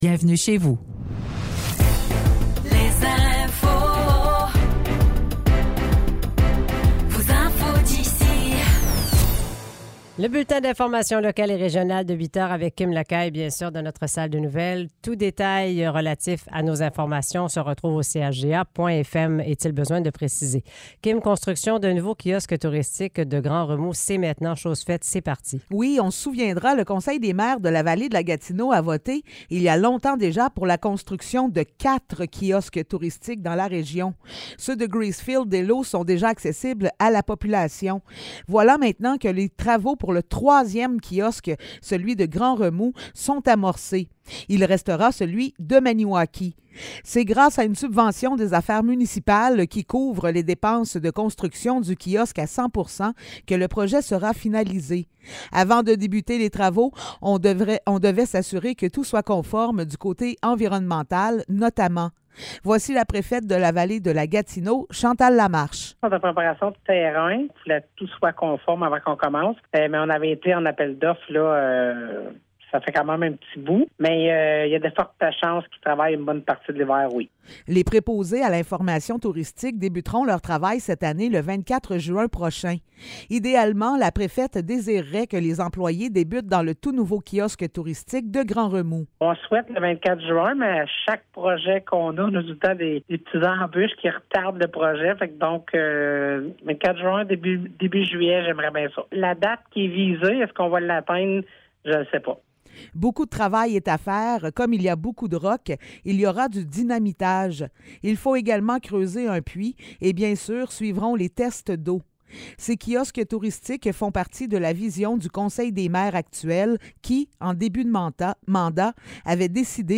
Nouvelles locales - 6 décembre 2023 - 8 h